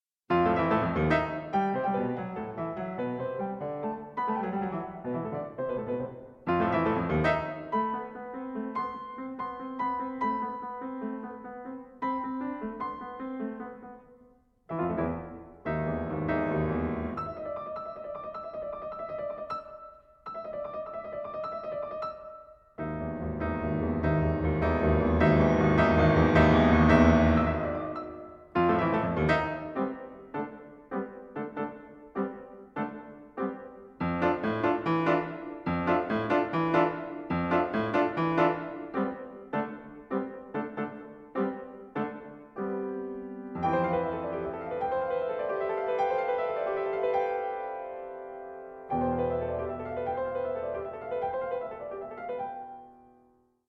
piano and prepared piano